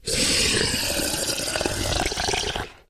Divergent / mods / Soundscape Overhaul / gamedata / sounds / monsters / psysucker / idle_0.ogg